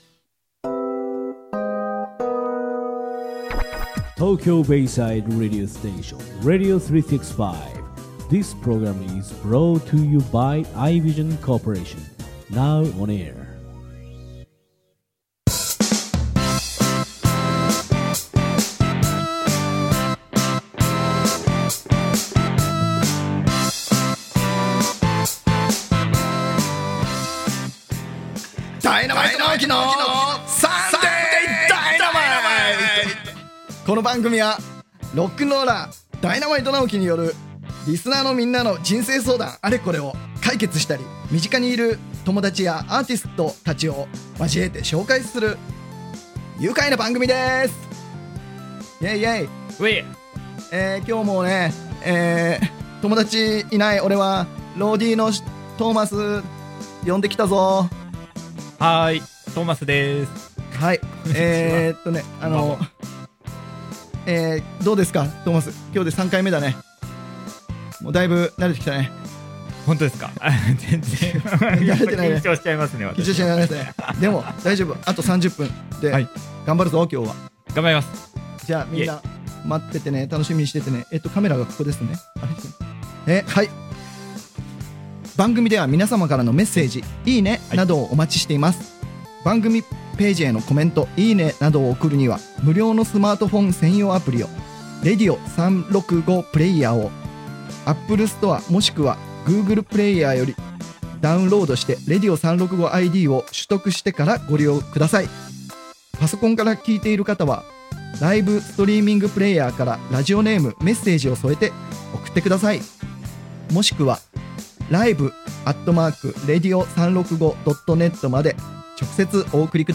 【この音源は生放送のアーカイブ音源となります。】